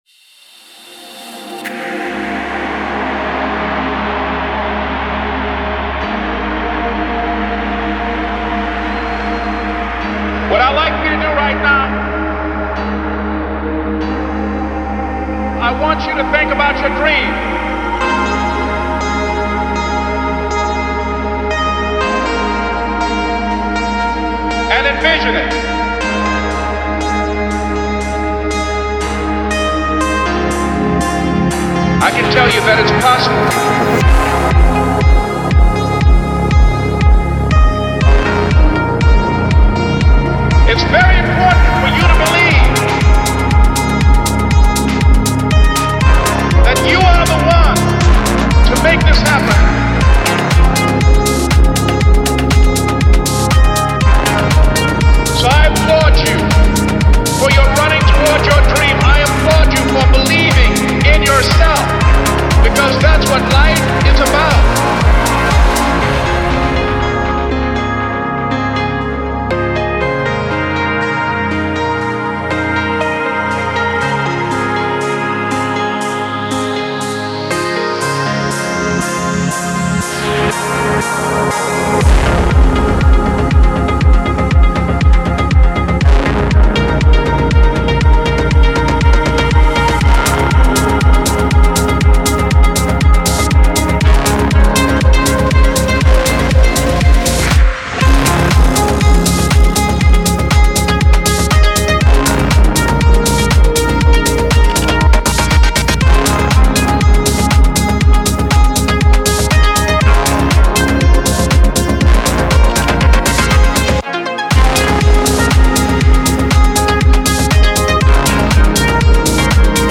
это атмосферная трек в жанре эмбиент